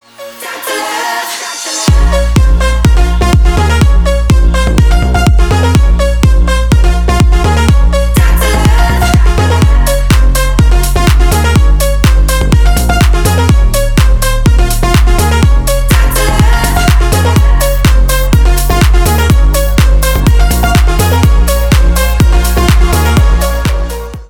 Поп Музыка # Электроника